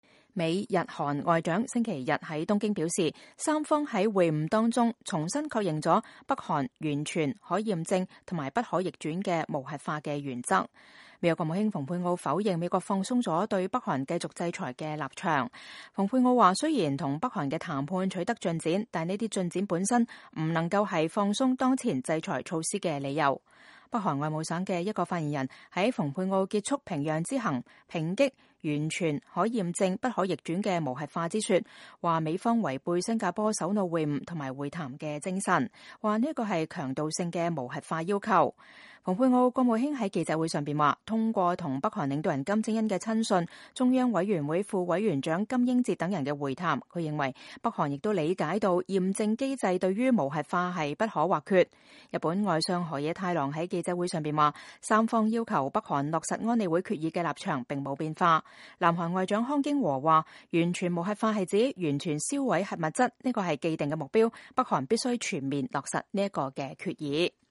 美國國務卿蓬佩奧在東京參加美日韓外長聯合新聞發布會。